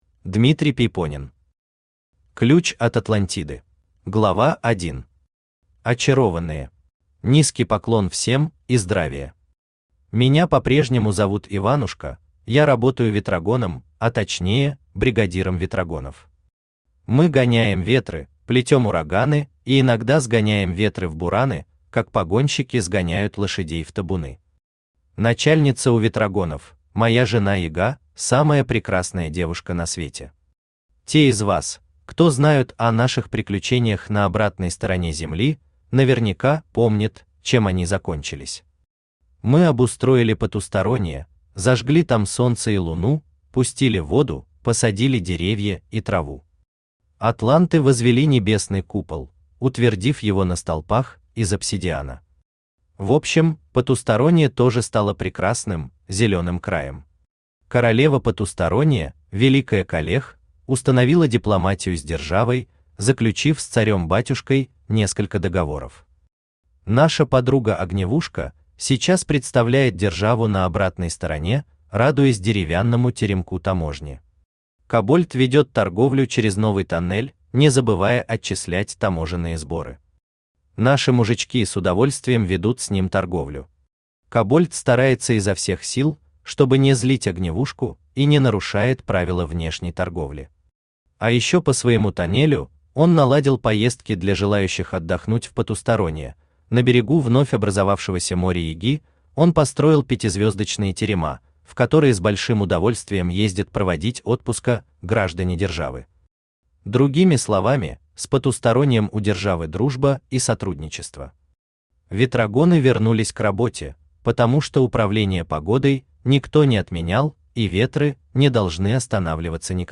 Aудиокнига Ключ от Атлантиды Автор Дмитрий Пейпонен Читает аудиокнигу Авточтец ЛитРес.